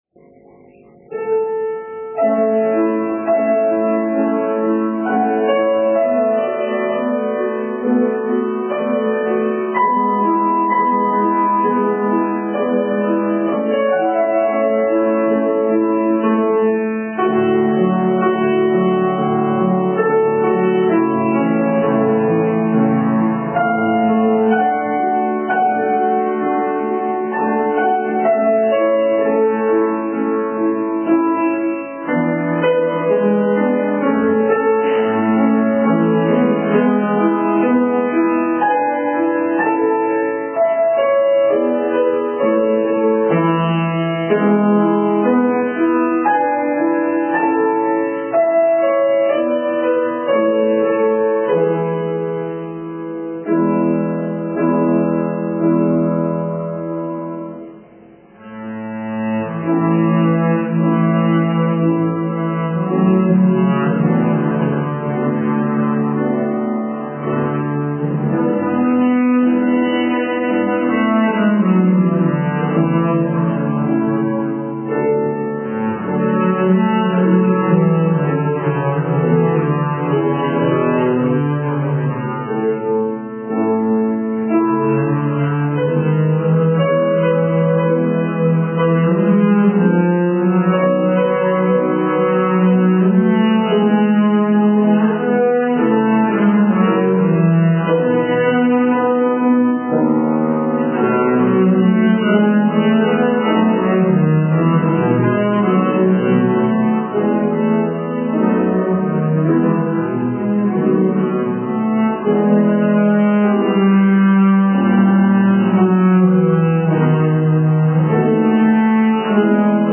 Ogólnopolskiego Konkursu Interpretacji Muzycznej
kontrabas